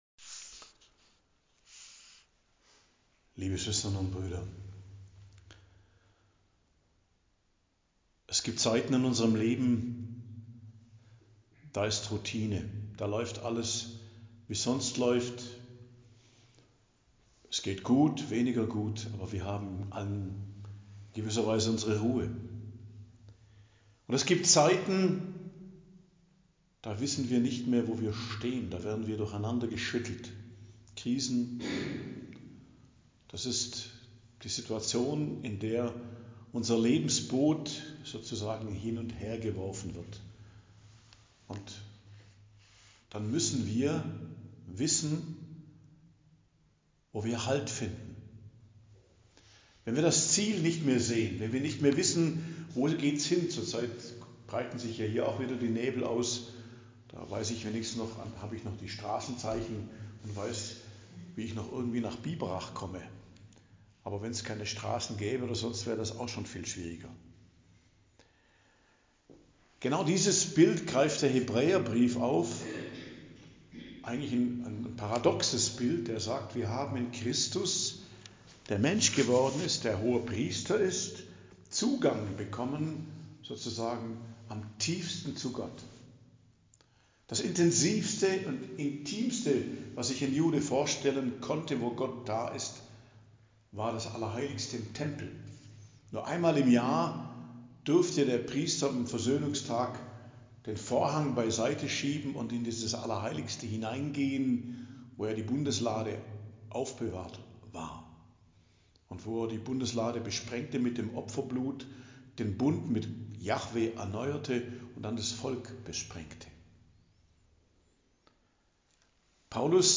Predigt am Dienstag der 2. Woche i.J. 21.01.2025